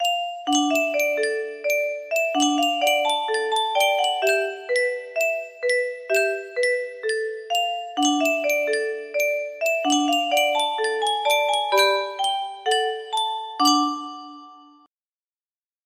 Hole punch paper strips Export